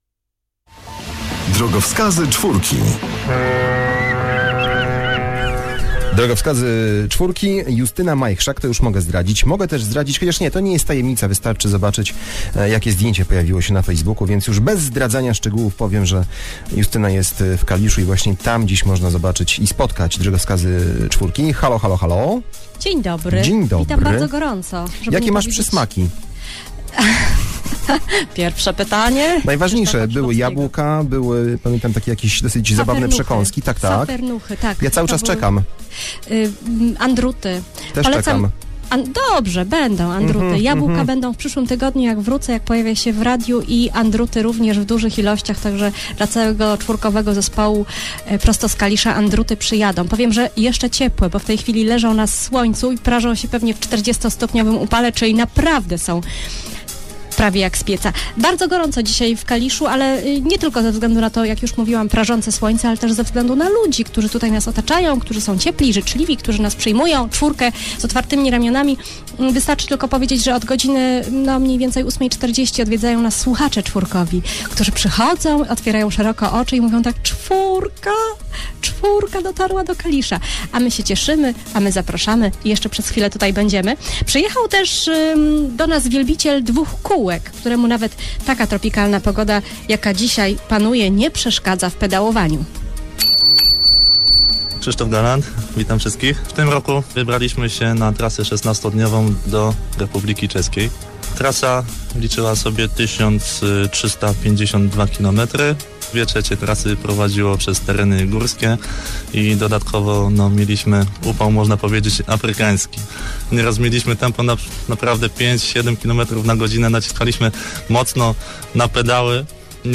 Wywiad z moim udziałem po przyjeździe z Czech – 01 sierpnia 2013 do radiowej “Czwórki”: